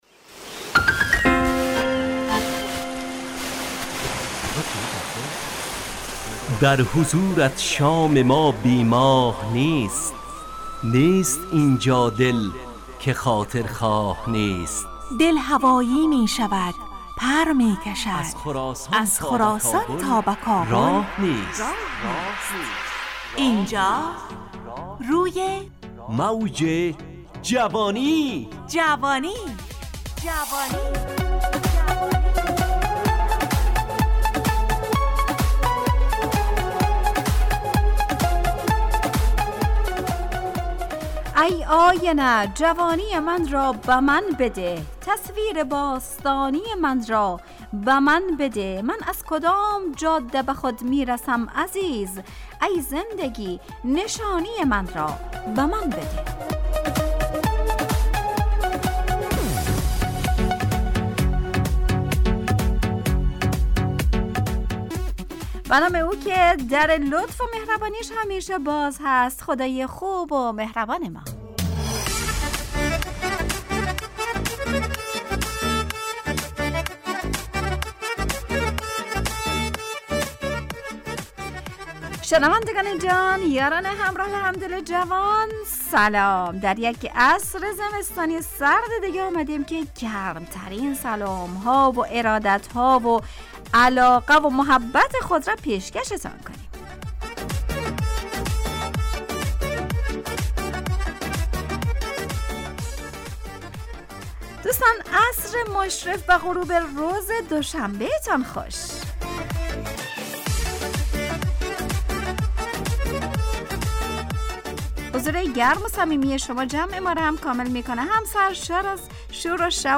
روی موج جوانی، برنامه شادو عصرانه رادیودری.
همراه با ترانه و موسیقی مدت برنامه 55 دقیقه . بحث محوری این هفته (عیادت) تهیه کننده